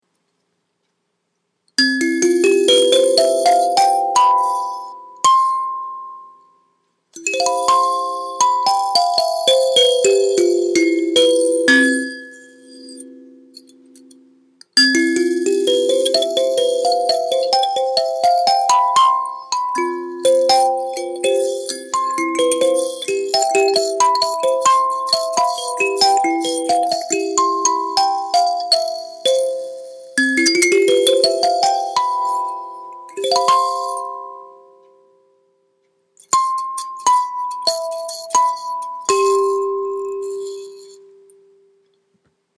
栗 a20 7,200円 1.7cm厚の栗の木の板で硬い 真鍮、鉄ネジ(茶） 荏胡麻油仕上げで焼ごてデザイン 弦は0.7mm厚x3mm幅のバネ綱でソフトタッチ Cからのオキナワ音階で11弦2オクターブ クリアできれいな音です 13.0cmx10.0cmx3cm(弦の高さまで) 約140gです ♫試聴♥